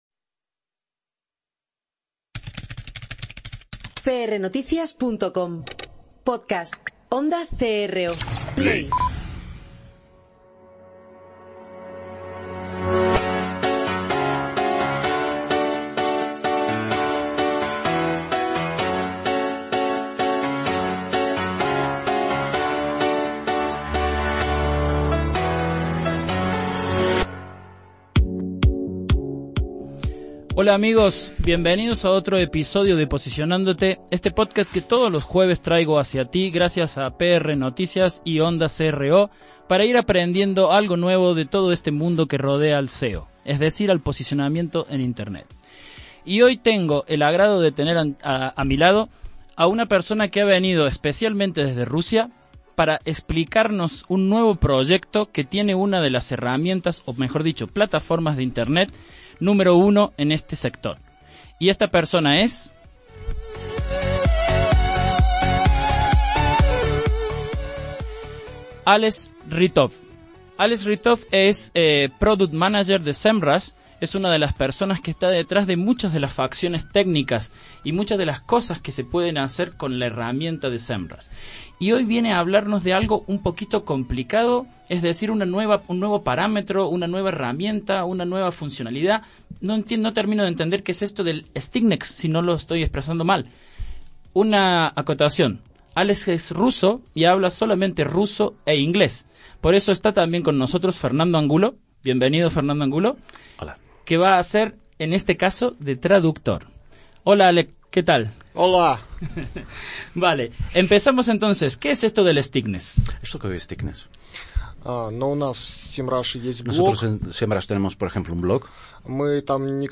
Como de costumbre, tengo conmigo a un invitado muy especial.